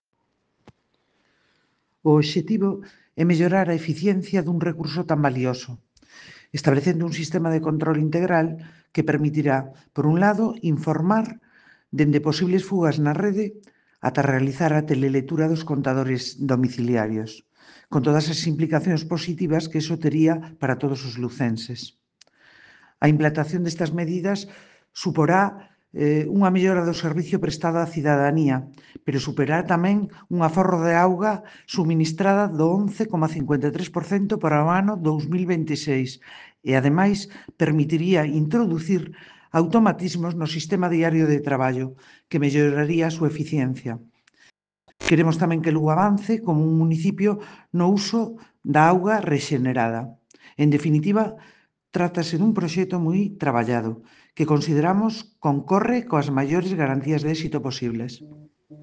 A alcaldesa de Lugo, Paula Alvarellos, informou esta mañá de que o Concello de Lugo concorrerá á terceira convocatoria dos fondos PERTE para a mellora da eficiencia do Ciclo Urbano da auga no marco do Plan de Recuperación, Transformación e Resiliencia. Deste xeito, o Goberno local opta a 2 millóns de euros destinados á dixitalización e sectorización das súas redes de abastecemento e saneamento.